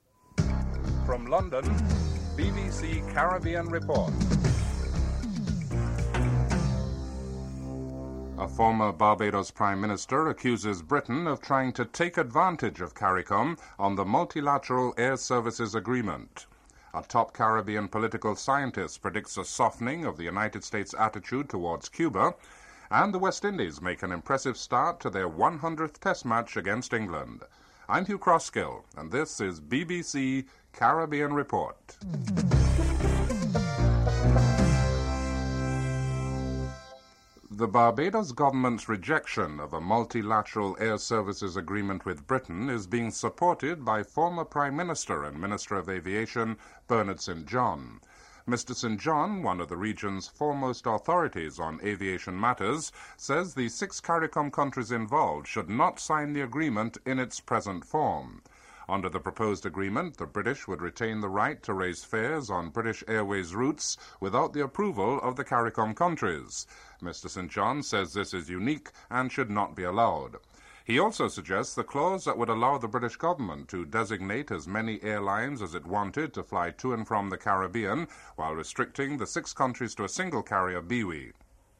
Unrelated clip at the beginning of the report.
1. Headlines (00:00-00:56)